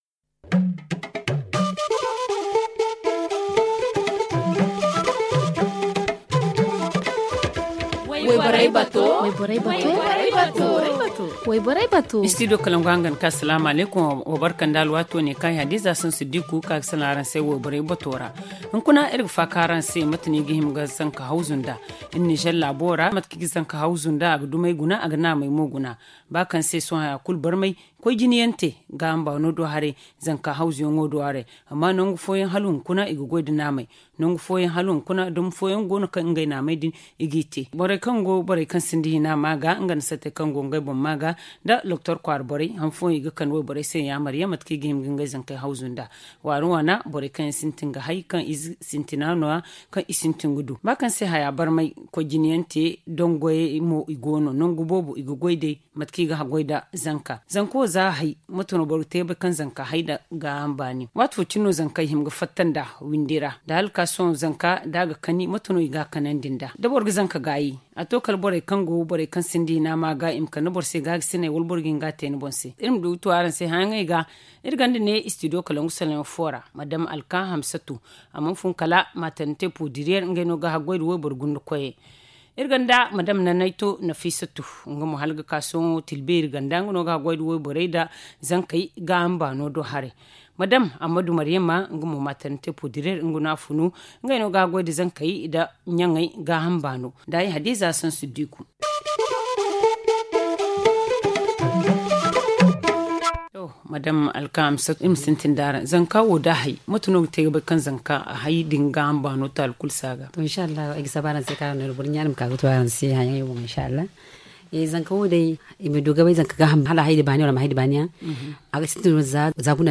Le forum en zarma https